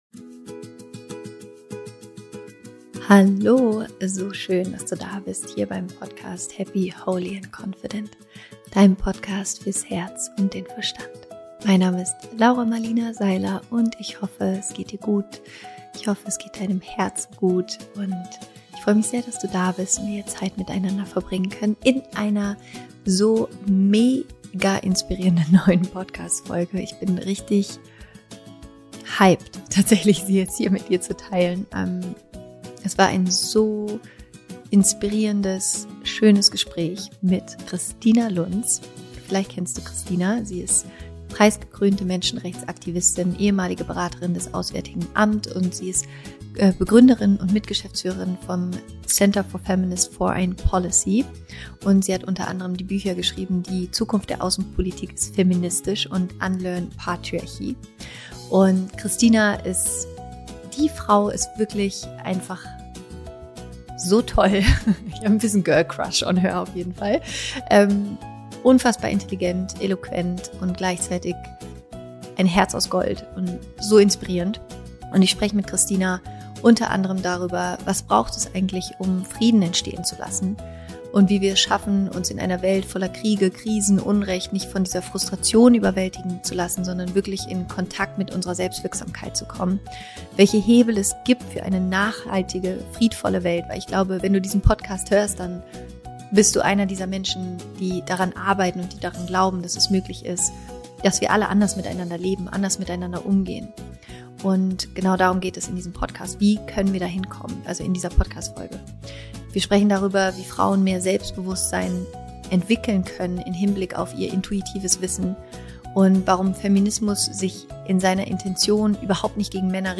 Warum es als Frau so wichtig ist, in die eigene Kraft zu kommen – Interview mit Kristina Lunz
Heute habe ich im Podcast die großartige Kristina Lunz zu Gast, die u. a. dafür antritt, Frauen in der Gesellschaft und der Politik eine stärkere Stimme zu verleihen.